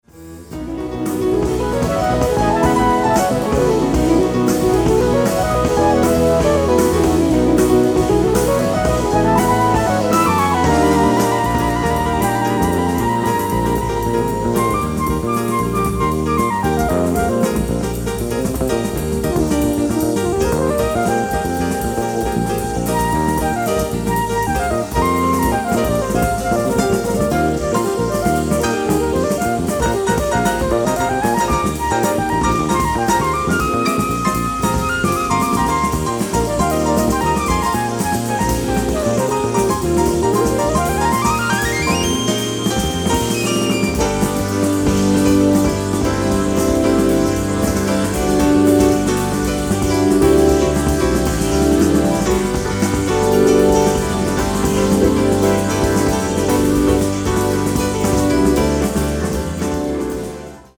piano, fender rhodes, synthesizers
fretless electric bass
drums